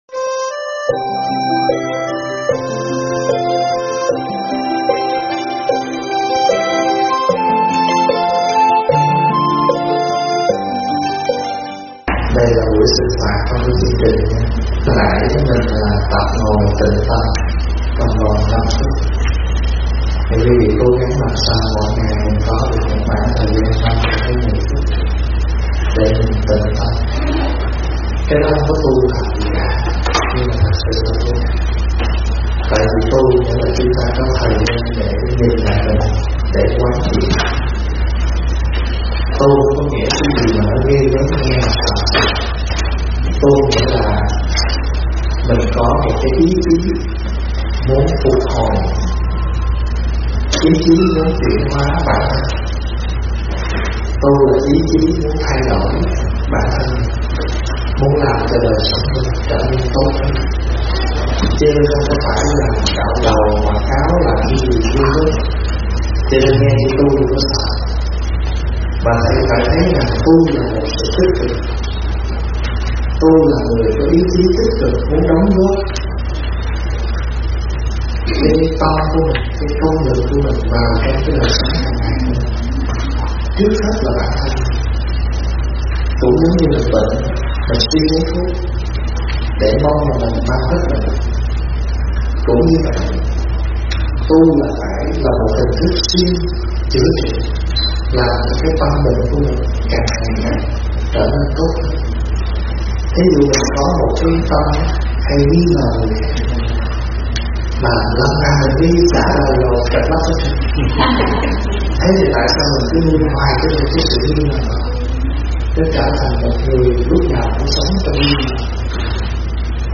Vấn đáp Tu Là Thay Đổi Bản Thân